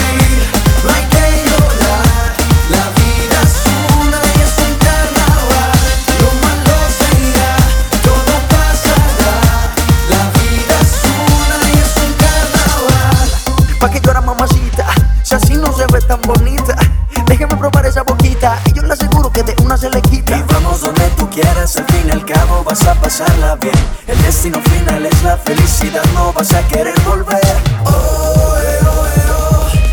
Genre: Urbano latino